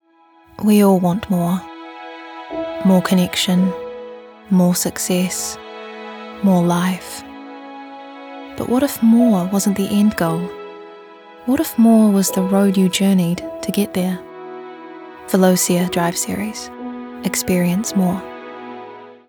Her voice spans from warm and smooth to friendly and energetic, maintaining a sense of trustworthiness, sophistication, and eloquence that suits a variety of voice work.
smooth/sophisticated